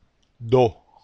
The following consonant is D. Nor has special rules and in the “flat” syllable sounds as follows: